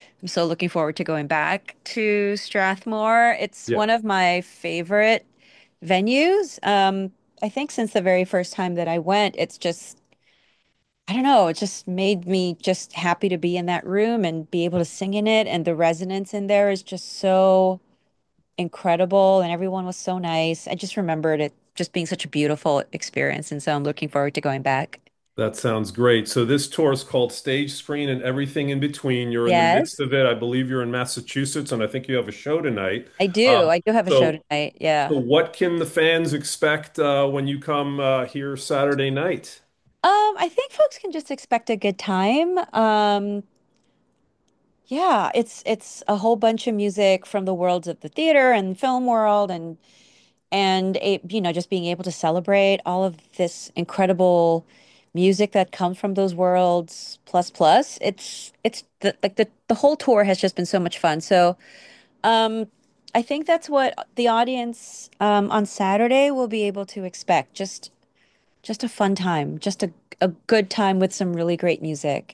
Salonga talked with WTOP before her Thursday night show in Amherst, Massachusetts.